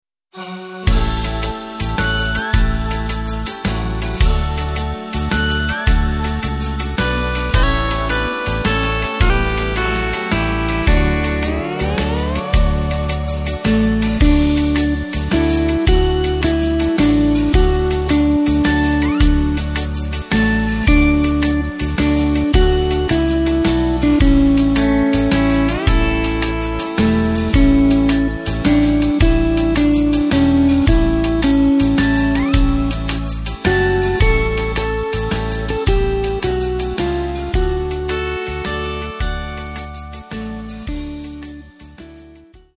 Takt:          3/4
Tempo:         108.00
Tonart:            D
SeemannsLied aus dem Jahr 1983!
Playback mp3 Demo